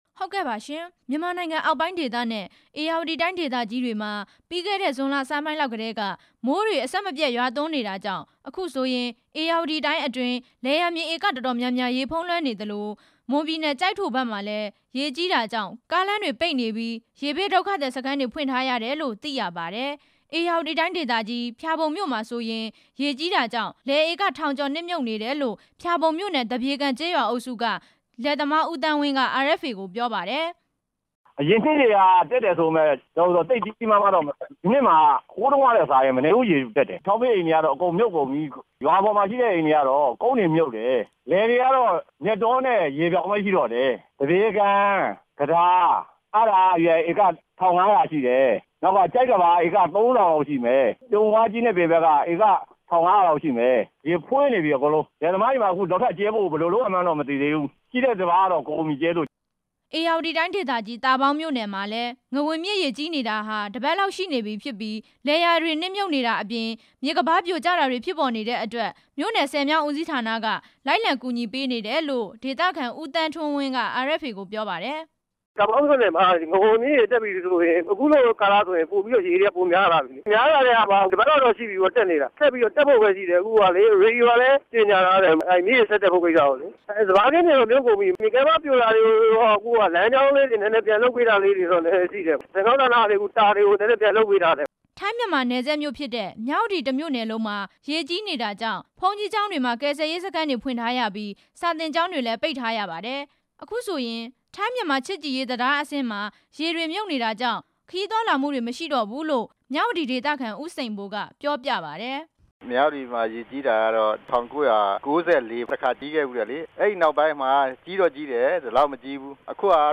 မွန်ပြည်နယ်၊ ကရင်ပြည်နယ်နဲ့ ဧရာဝတီတိုင်း ရေဘေးအခြေအနေ တင်ပြချက်